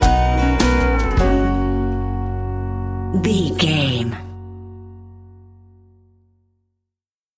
Ionian/Major
drums
acoustic guitar
violin
Pop Country
country rock
bluegrass
uplifting
driving
high energy